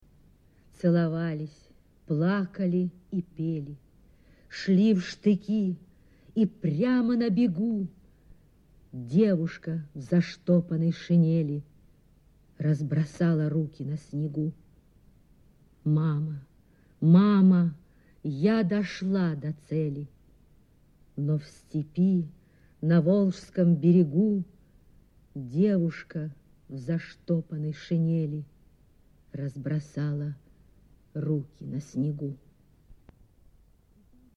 Drunina-Celovalis-plakali-i-peliquot-chitaet-Klara-Luchko-stih-club-ru.mp3